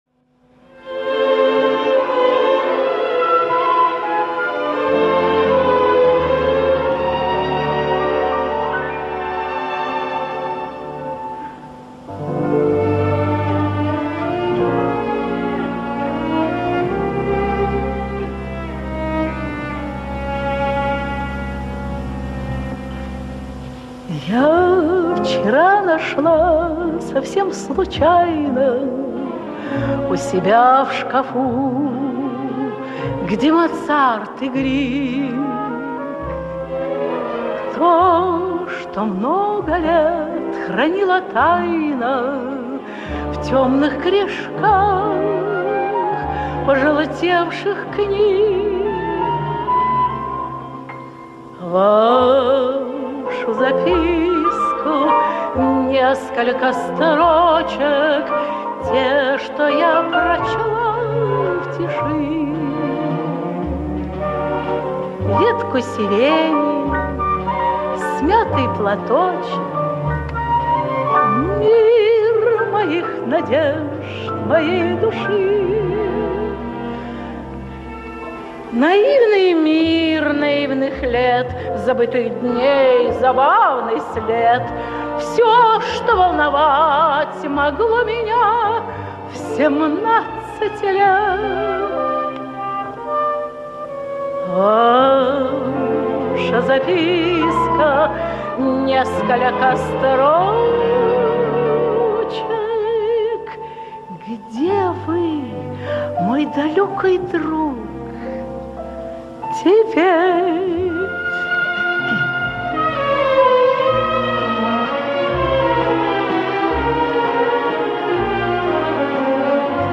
на юбилейном концерте в Колонном зале Дома Союзов